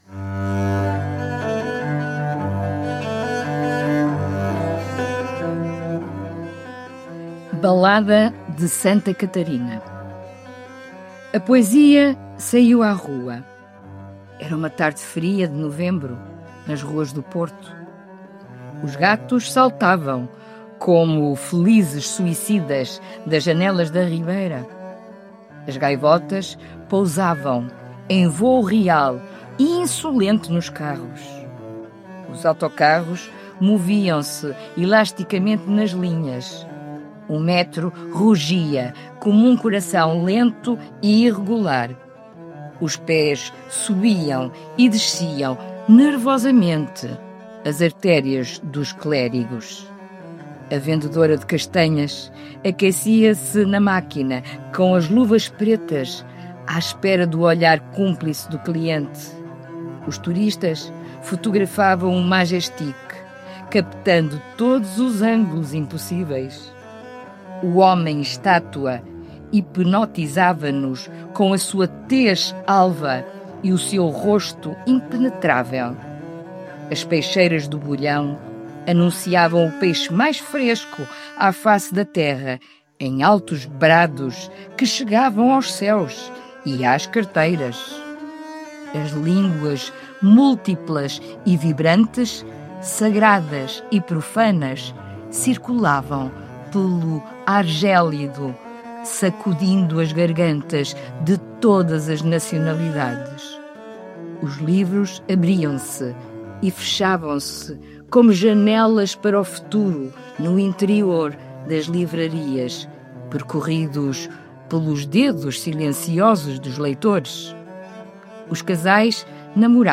Música: Inspiring Bach Cello Suite, por Orchestralis, Licença Envato – Free Files Single Use Policy.